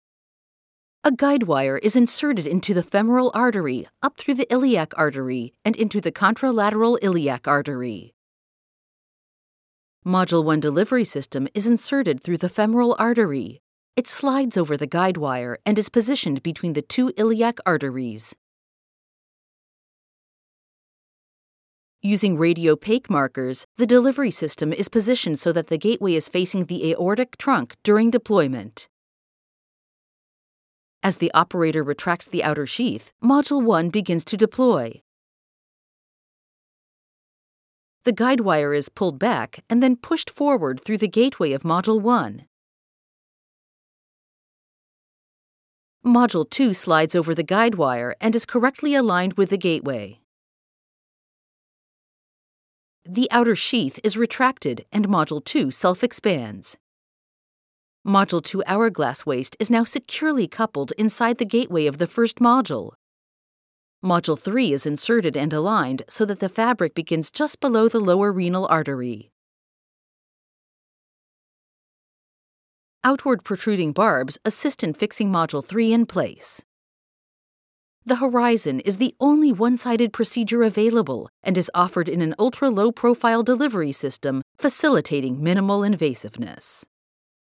Spécialisée dans l'e-learning et la narration technique, elle offre un doublage professionnel, chaleureux, autoritaire et rapide, idéal pour les projets d'entreprise, éducatifs et axés sur les personnages.
Narration médicale
* Studio traité acoustiquement, pour garantir un son propre et de haute qualité